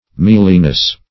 Mealiness \Meal"i*ness\, n. The quality or state of being mealy.